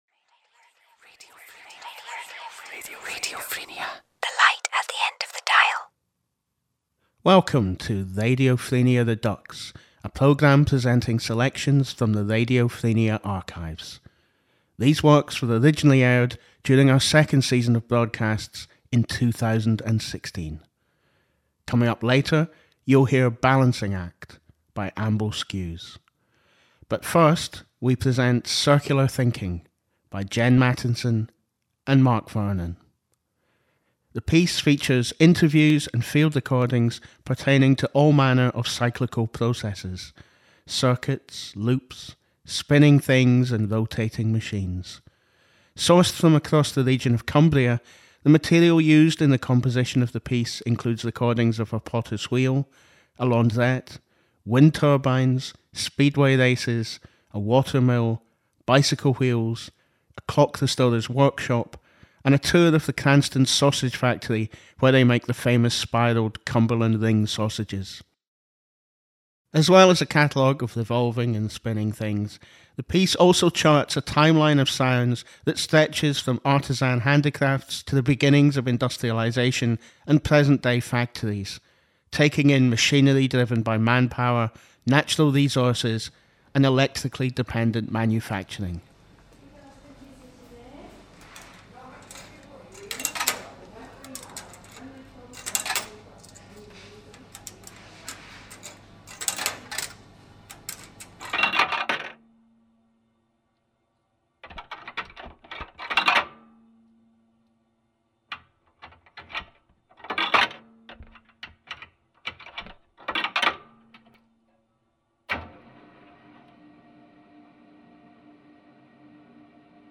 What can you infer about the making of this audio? –Interviews and field recordings from Cumbria pertaining to all manner of cyclical processes; circuits, loops, spinning things and rotating machines.